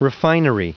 Prononciation du mot refinery en anglais (fichier audio)
Prononciation du mot : refinery